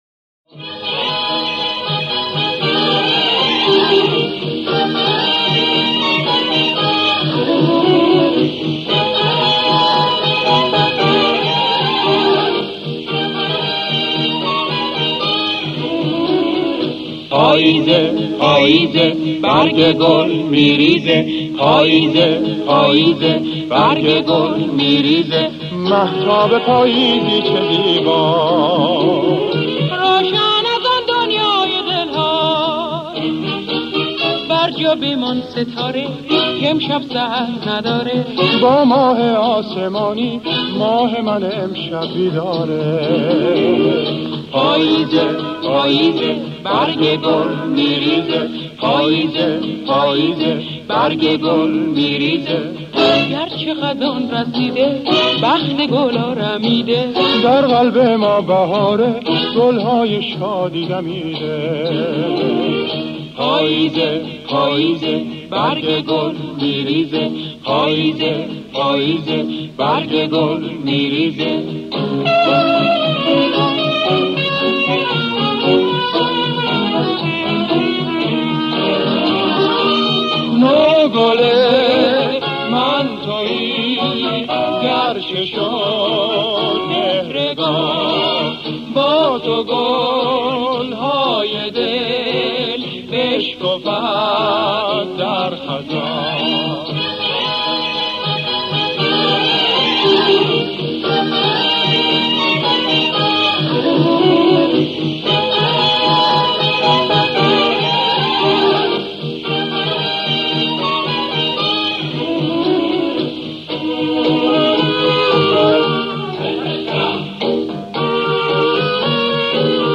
در مایه اصفهان